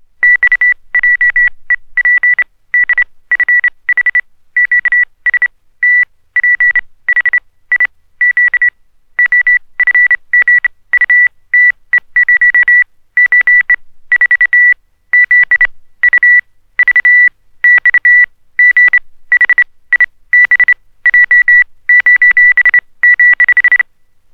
• morse code oscillator - high pitched.wav
morse_code_oscillator_-_high_pitched_K29.wav